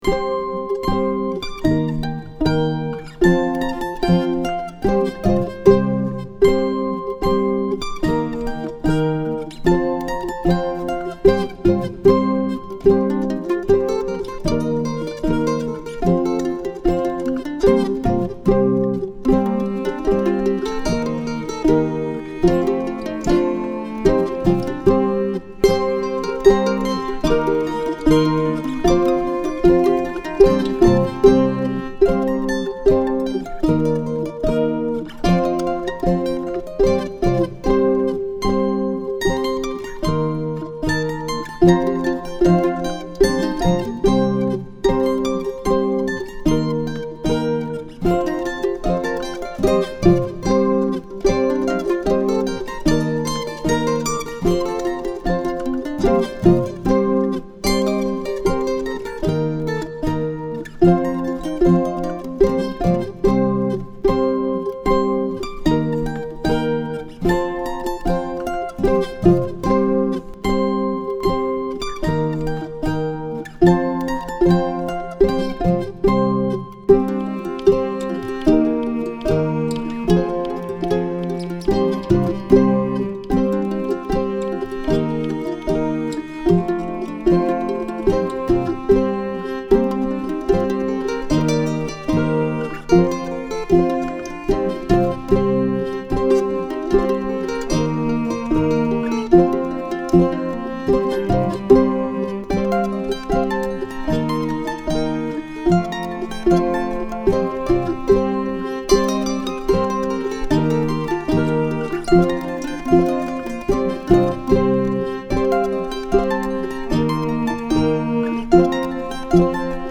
twomandolins.mp3